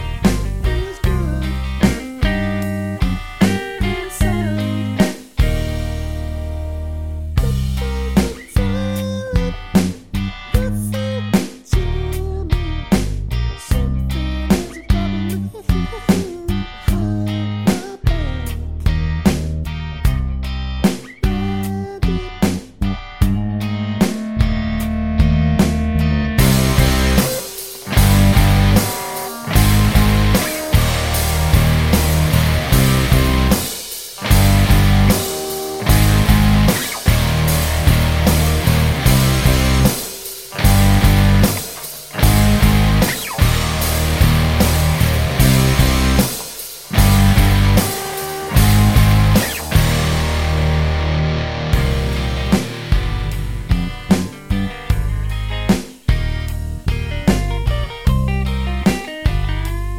no Backing Vocals Indie / Alternative 4:17 Buy £1.50